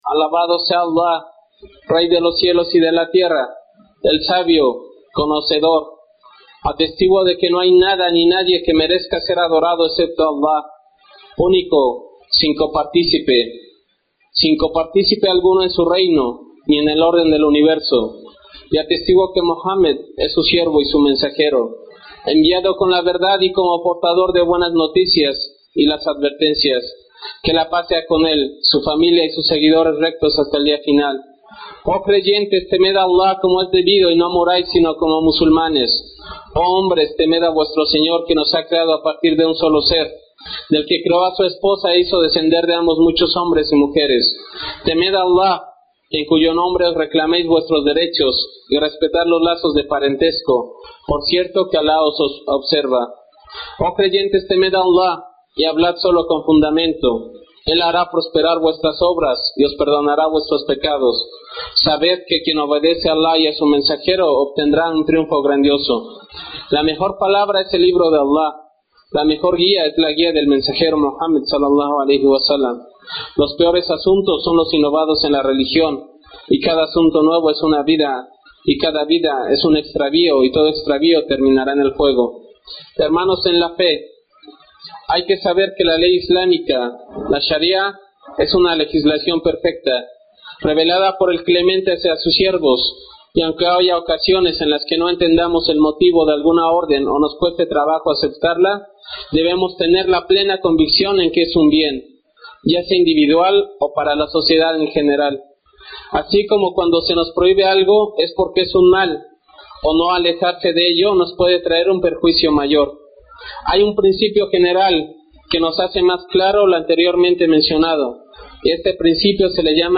Jutbah sobre el peligro de hablar sobre lo que uno no sabe sin conocimiento auténtico.